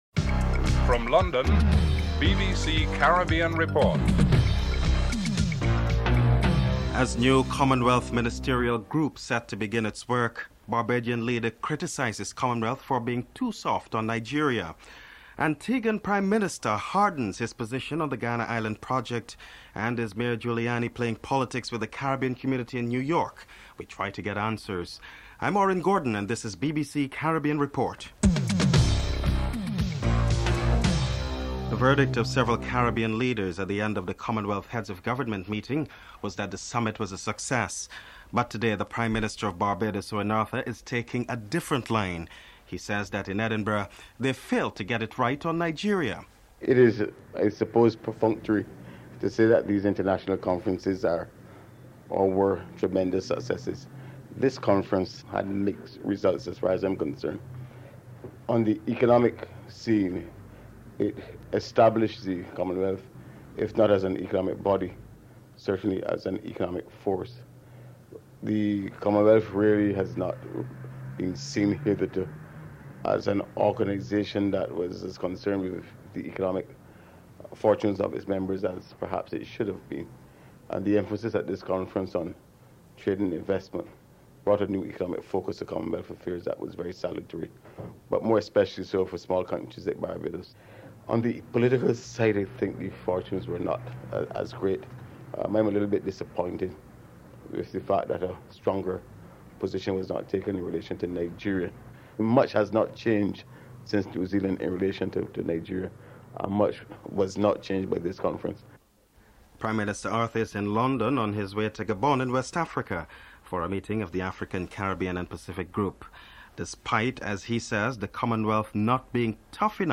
1. Headlines (00:00-00:30)
Prime Minister of Barbados Owen Arthur is interviewed (00:31-04:08)
3. The Antiguan Prime Minister hardens his position on the Guiana Island project. Prime Minister Lester Bird is interviewed (04:09-07:01)